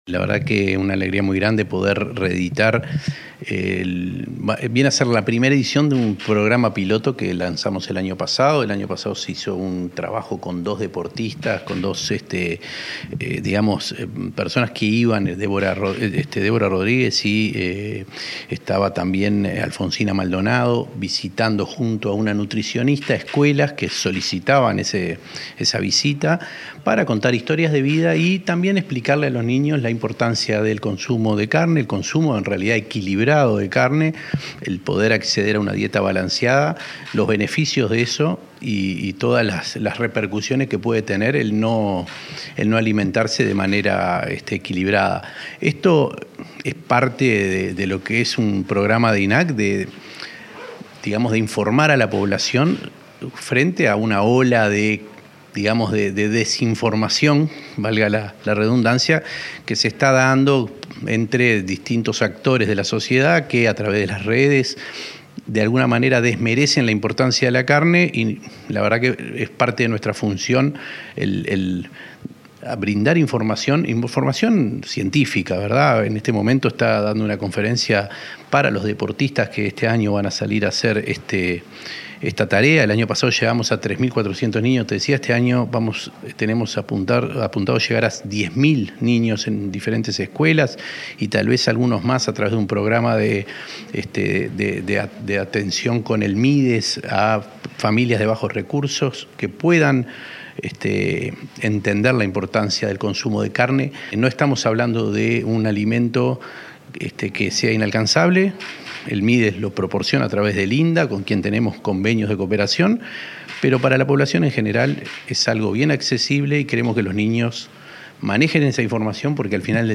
Entrevista al presidente de INAC, Conrado Ferber